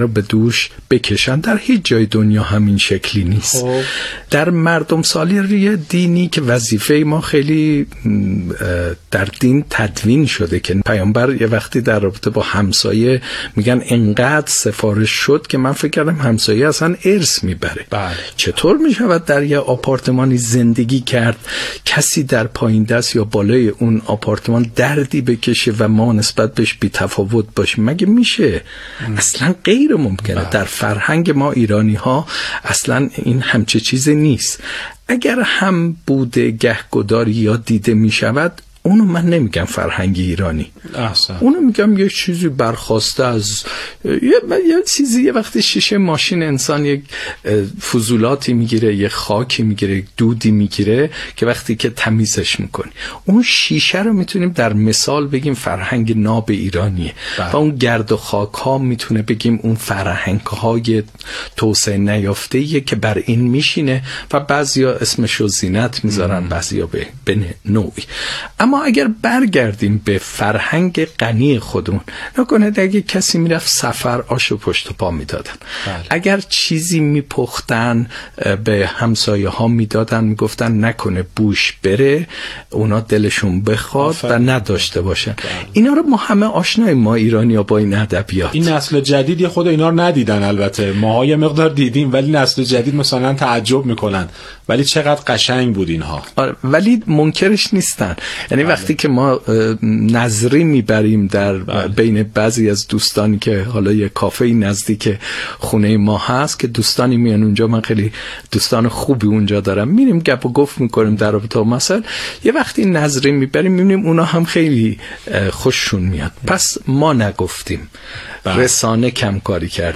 مشروح گفتگو